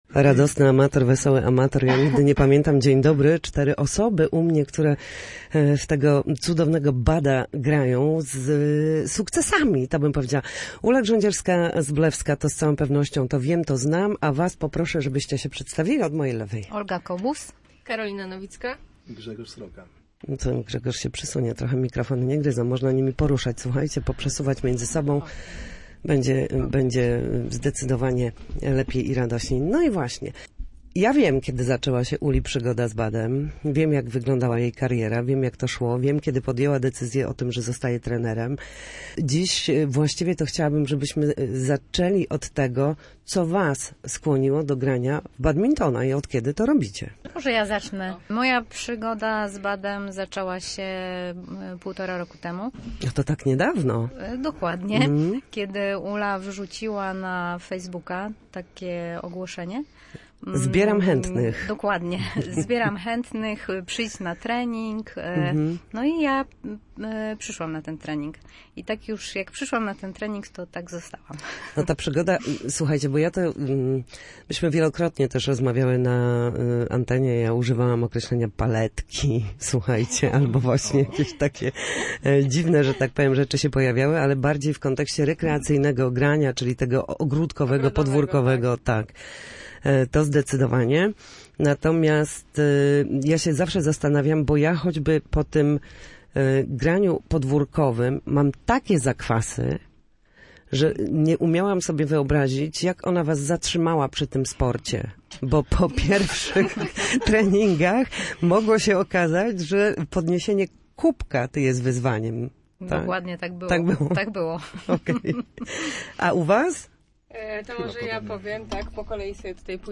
W słupskim studiu goście, dla których sport stał się nie tylko hobby, ale i sposobem na życie oraz przyjaźń.